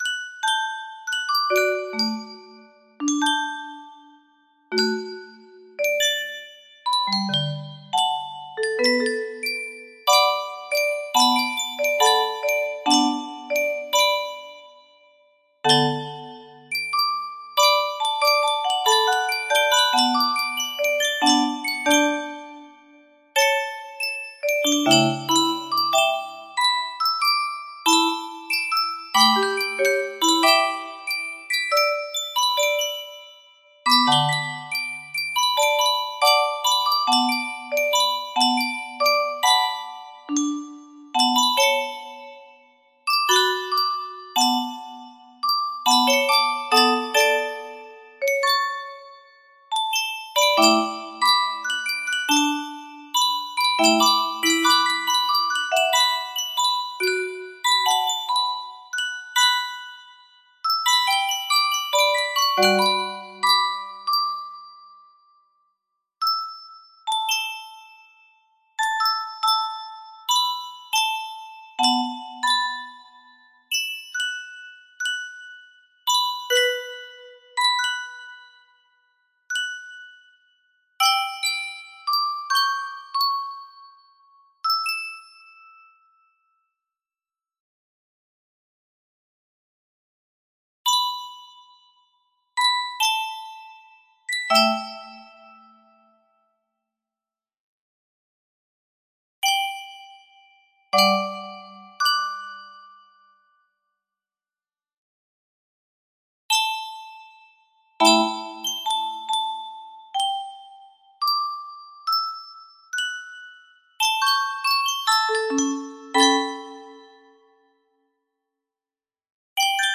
music box melody
Full range 60
all done, no reds, original composition manual, midi keyboard, wispow free piano app.